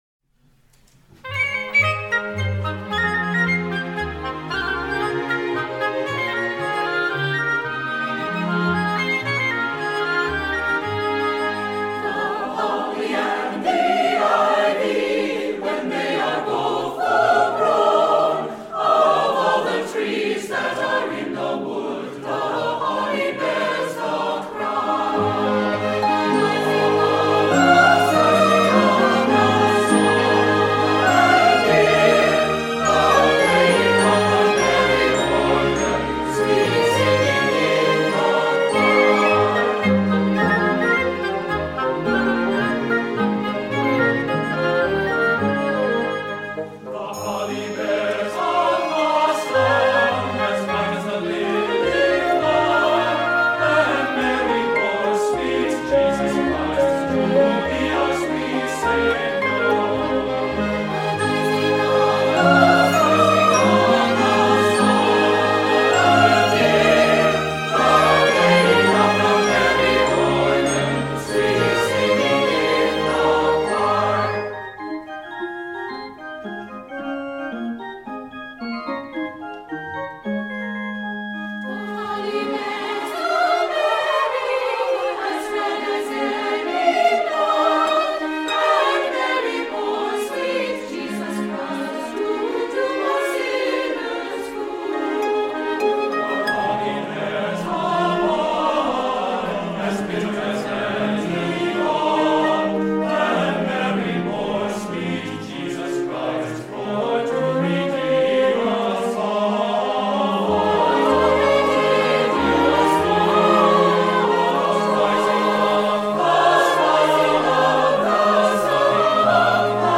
CHAMBER Orchestra & Chorus
(An arrangement of the traditional English carol)
For most of the piece, the organ softly backs up the chorus.
BRASS:  1 Horn
HARP:  1 Harp